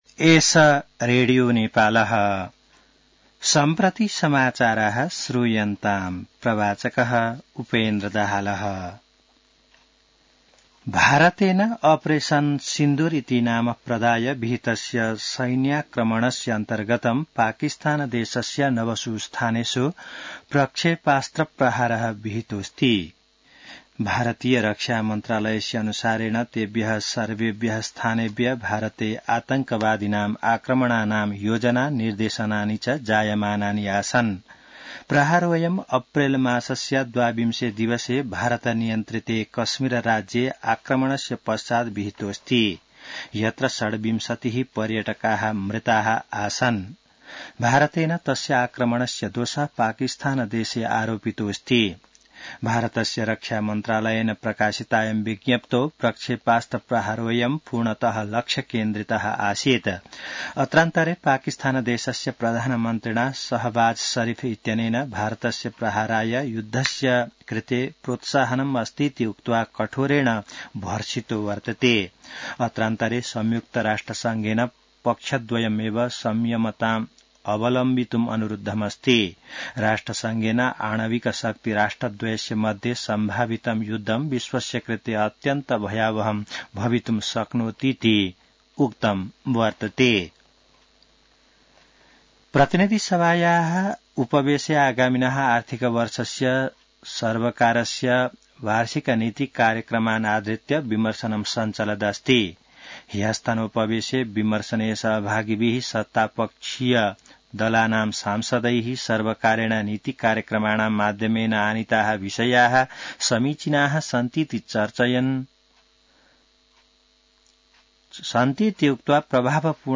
संस्कृत समाचार : २४ वैशाख , २०८२